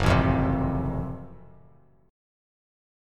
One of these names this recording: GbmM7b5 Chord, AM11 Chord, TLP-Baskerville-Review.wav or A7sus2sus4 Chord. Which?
GbmM7b5 Chord